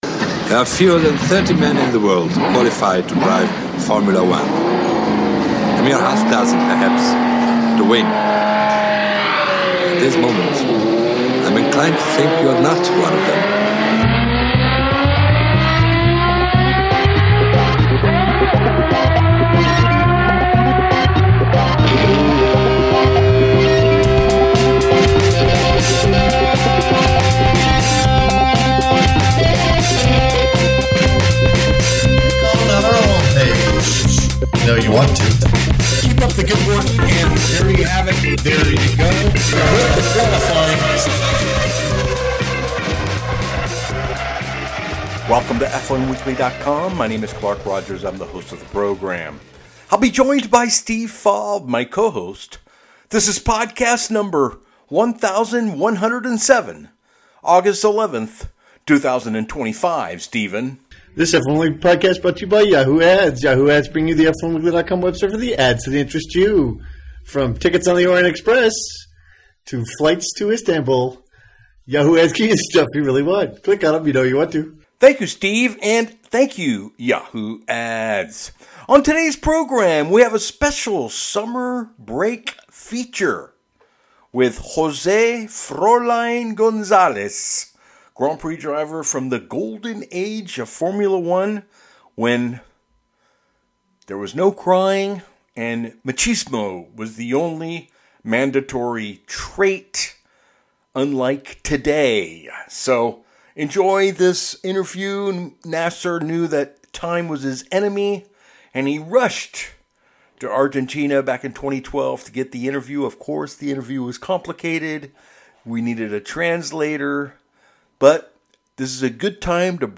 he goes all the way to Argentina to interview José Froilán González the driver that gave Enzo Ferrari his first Grand Prix victory!